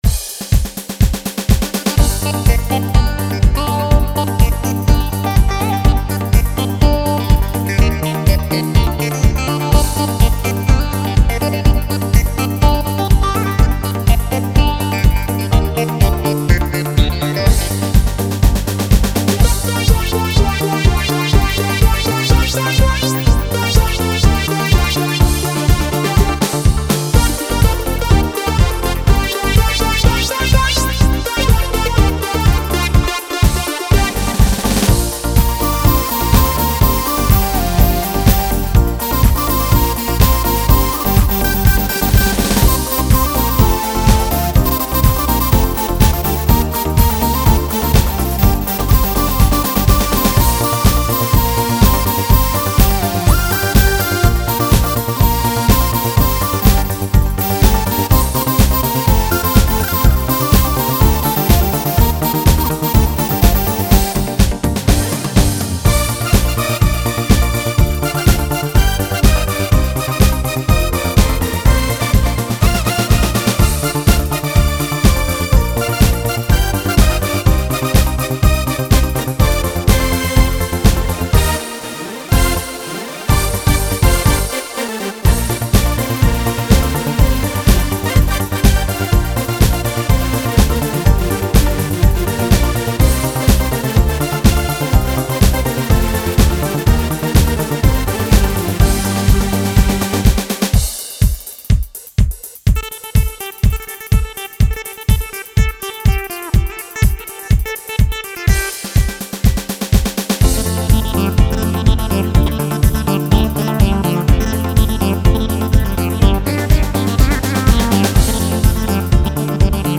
ז'אנרDance
BPM130